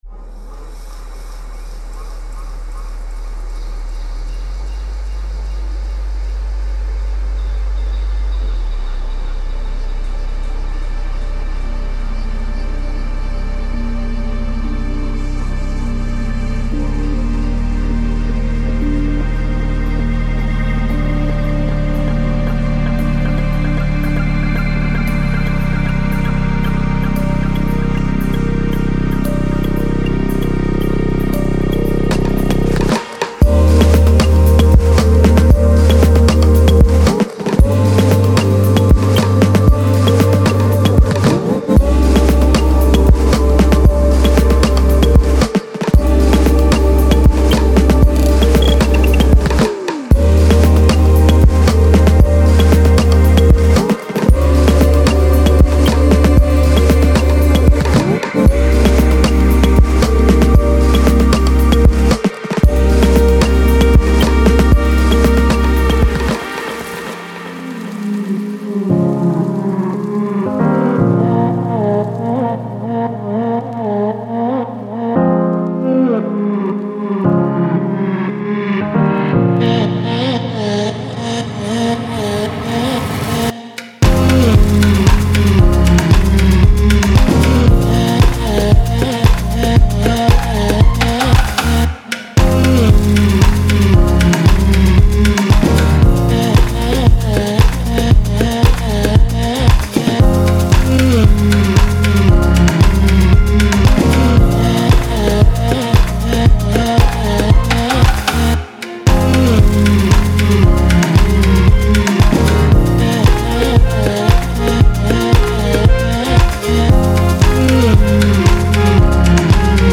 值得一提的是，该精品库中提供的SFX包含了一系列特征，从有机foley立管，闪烁的ym声到沉闷的色调。
• 76温暖的鼓声
• 20个郁郁葱葱的豪华氛围
• 14个厚重的低音圈（包括Midi文件）
• 25个大气旋律循环（包括Midi文件）
• 节奏-115PM